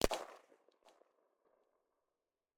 ar_firing_far.ogg